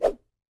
whoosh.mp3